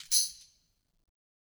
Tamb1-Shake_v1_rr1_Sum.wav